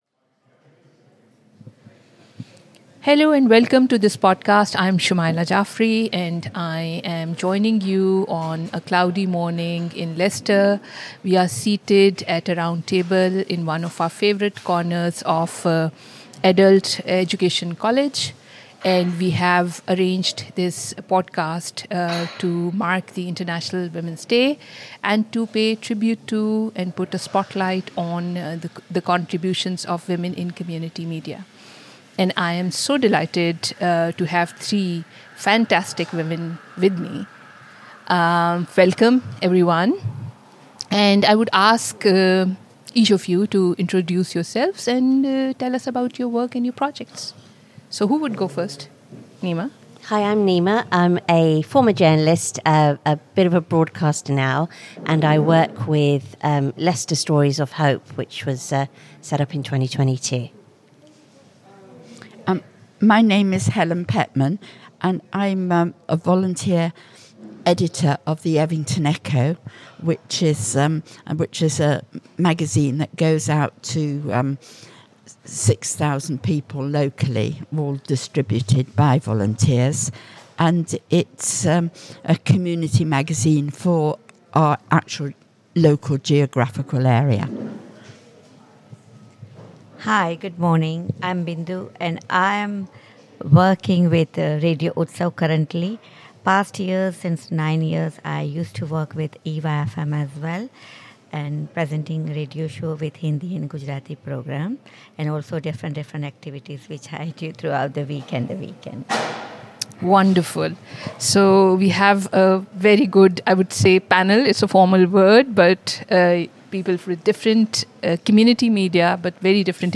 And how can everyday communication through radio, local journalism, and storytelling help rebuild trust between neighbours when misinformation and misunderstanding threaten to divide them? These were the questions explored in a discussion recorded for International Women’s Day, bringing together women who are actively involved in community media and local communication projects.